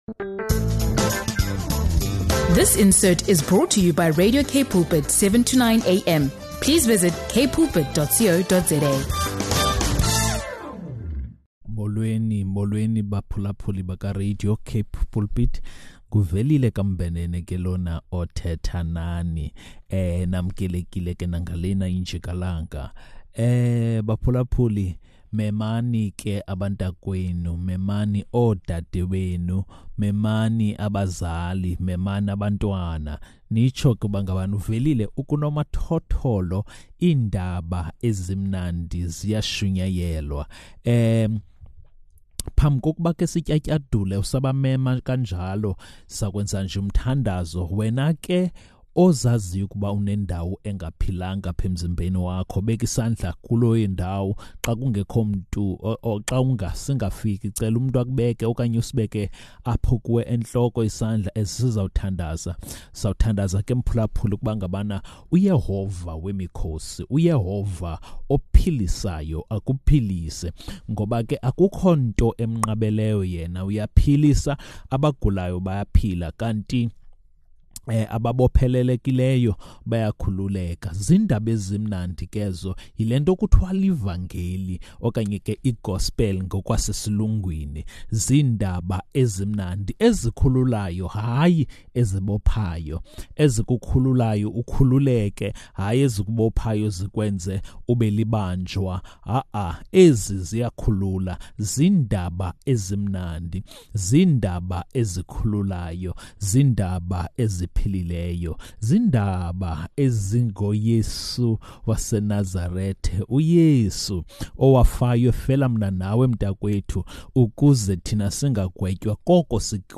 In this devotional